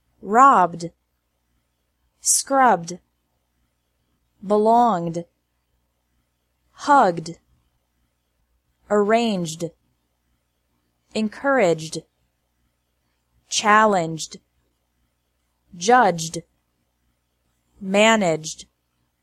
-ED pronounced like D
After regular verbs ending with a B / G / J sound